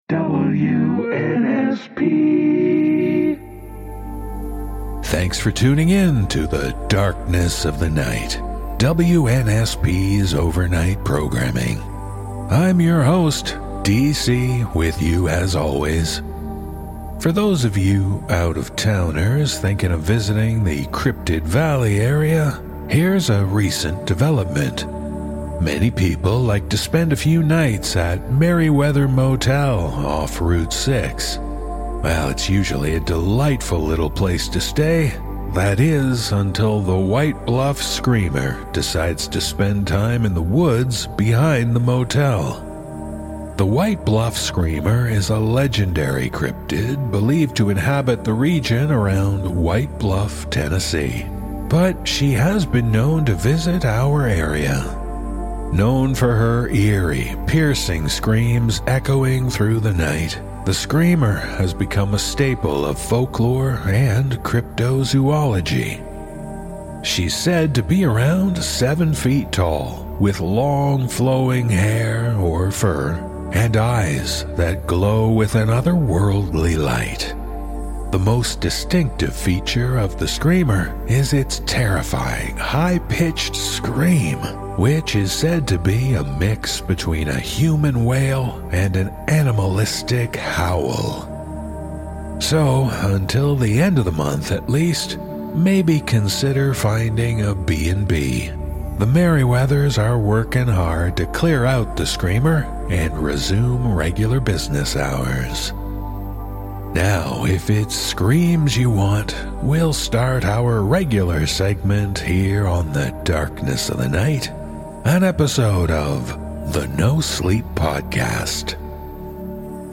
Radio Announcer